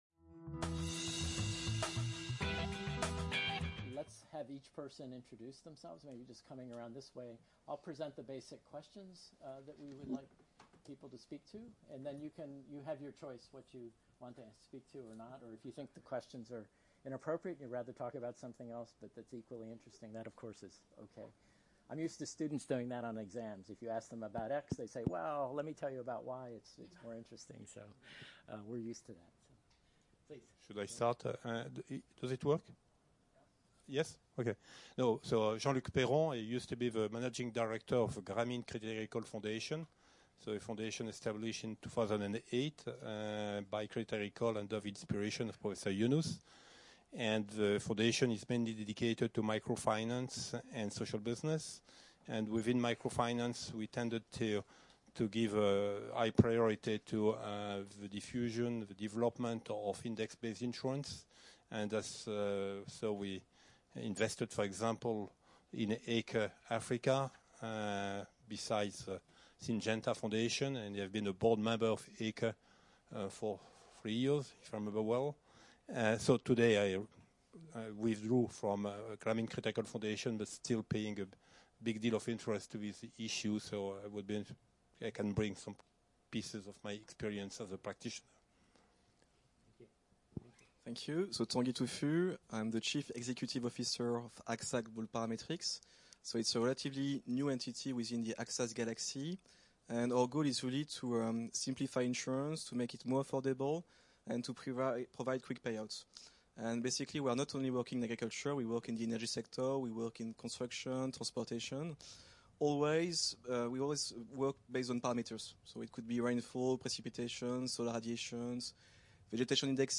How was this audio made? Workshop organised by the Fondation pour les études et recherches sur le développement international (FERDI), the Centre d'Etudes et de Recherches sur le Développement International (CERDI) and the Agence française pour le développement (AFD).